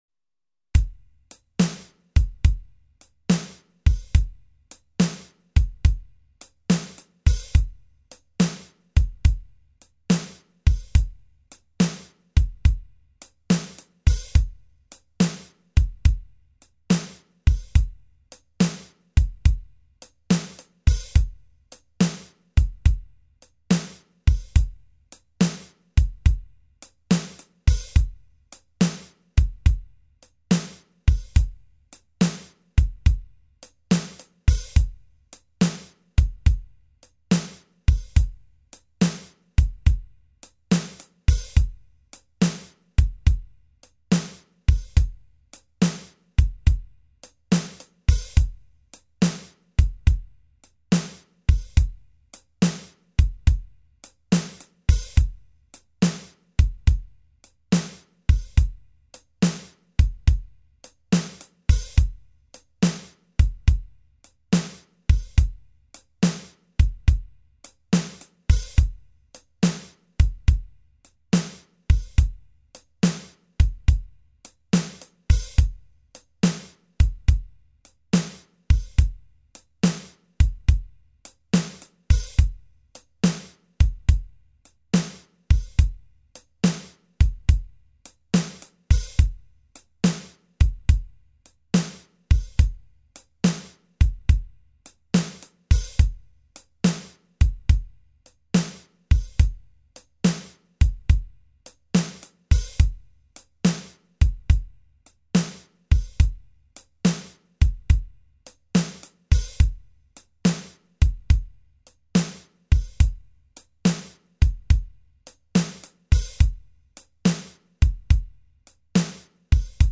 Drum Track
blues drum track1.mp3